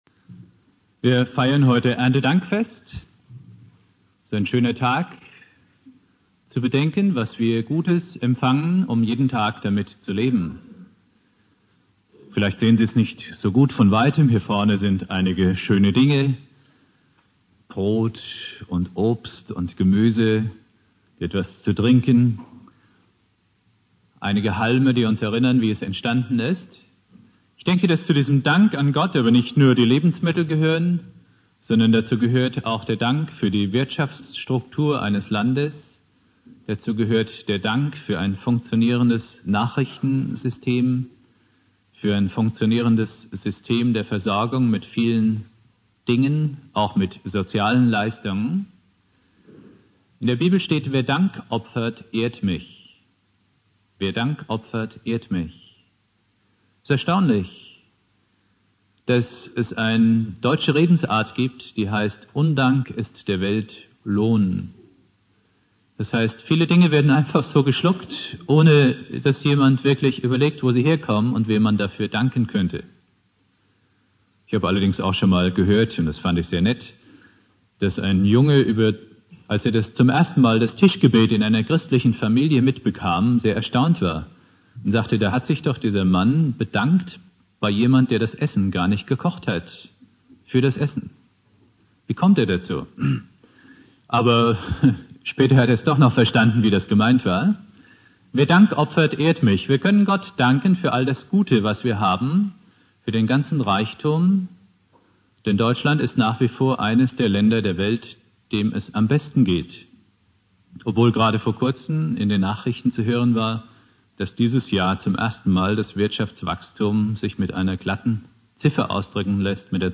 Predigt
(Erntedankfest) Bibeltext: Jesaja 49,1-6 Dauer: 26:53 Abspielen: Ihr Browser unterstützt das Audio-Element nicht.